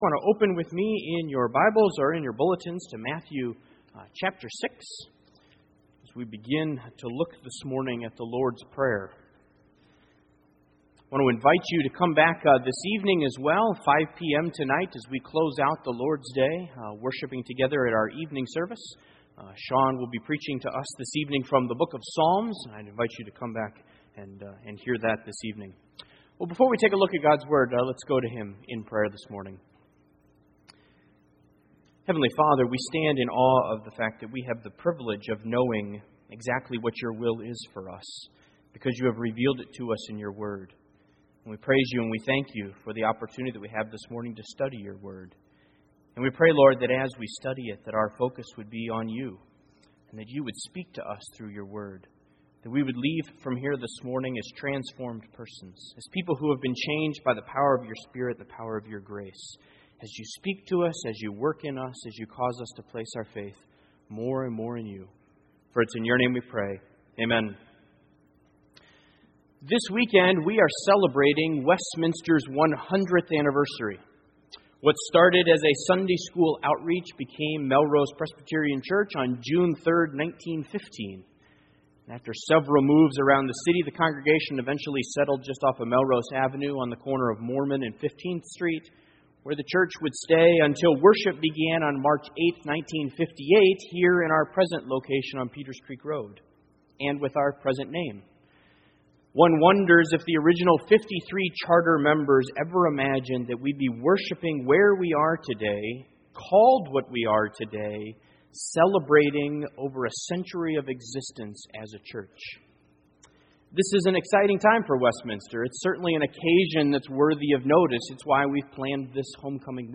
Matthew 6:9 Service Type: Sunday Morning What is it that sets Westminster apart from any other civic organization?